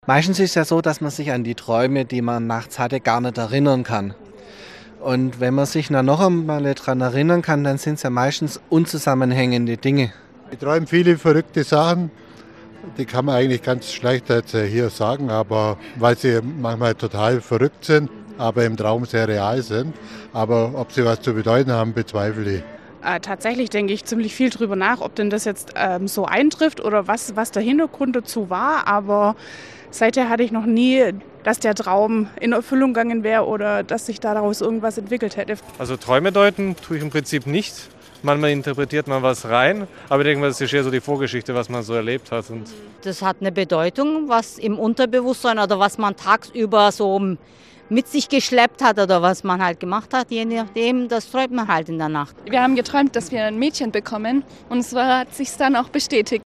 Umfrage: Was bedeuten Ihnen Träume?